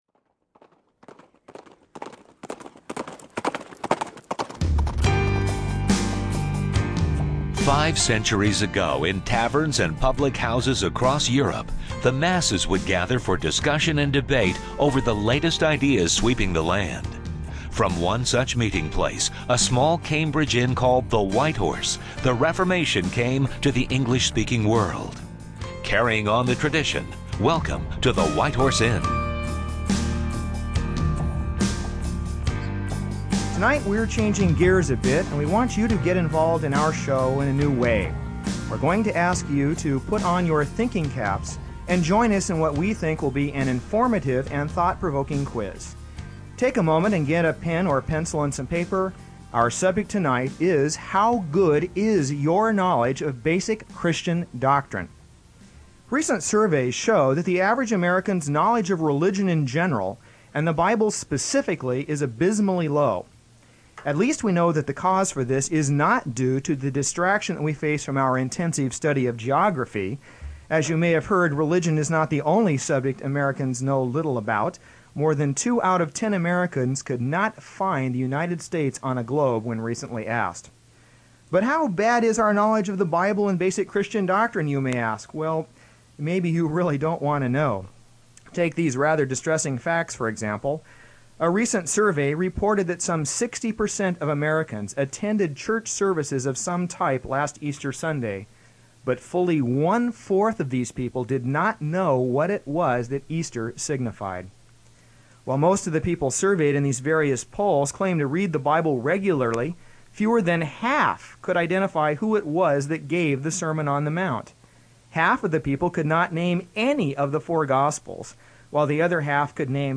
On this program the hosts ask a number of true or false questions and then walk through the answers, all with the goal of helping you to better understand…